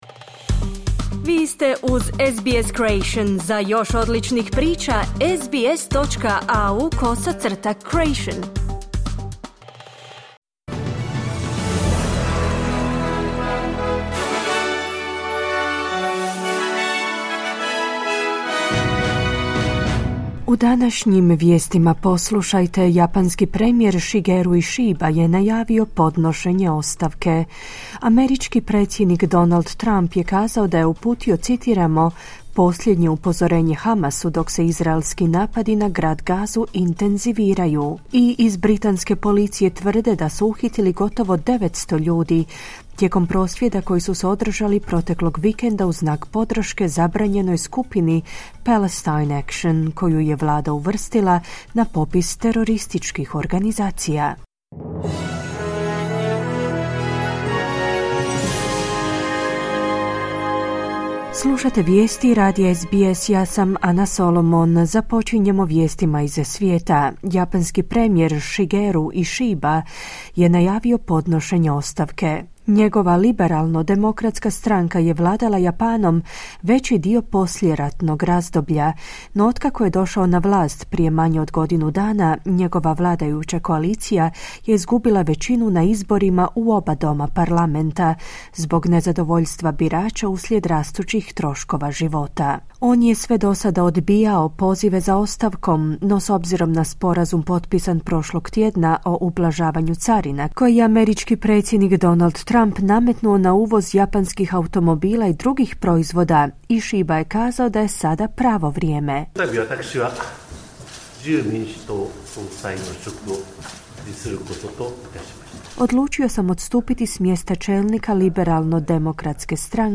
Vijesti, 8.9.2025.
Vijesti radija SBS na hrvatskom jeziku.